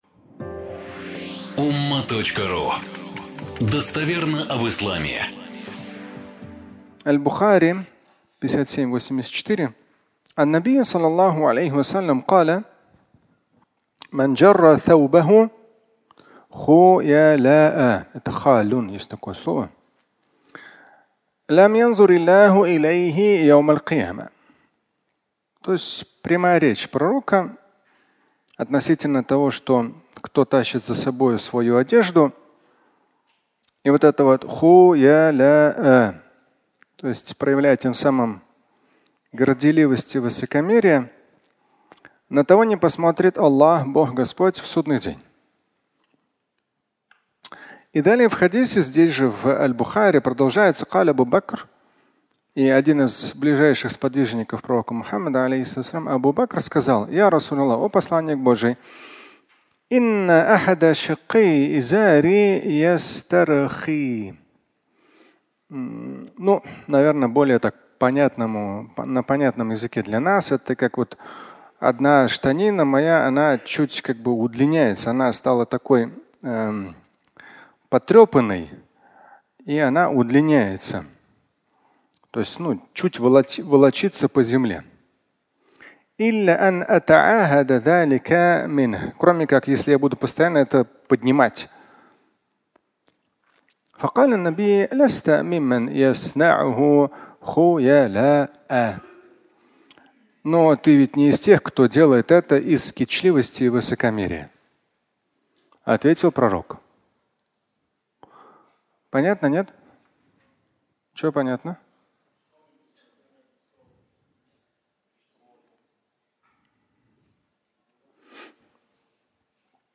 Штаны и Абу Бакр (аудиолекция)